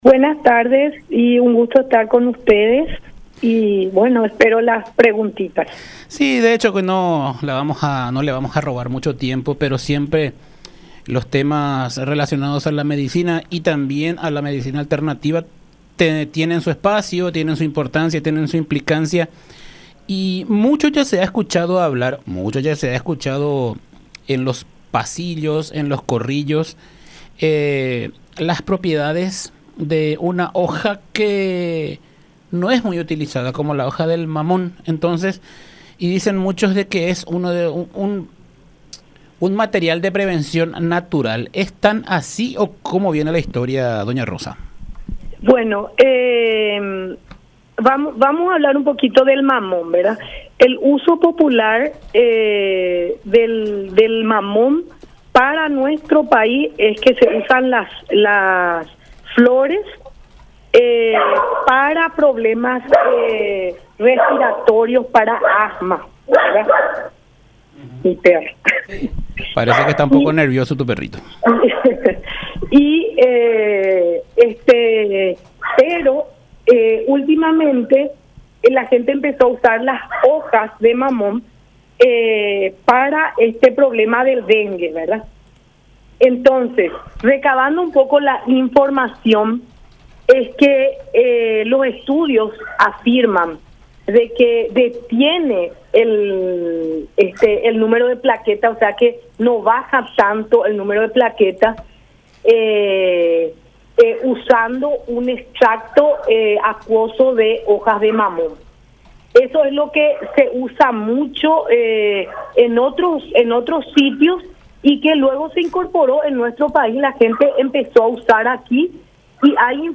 Docente investigadora de la UNA en comunicación con La Unión R800AM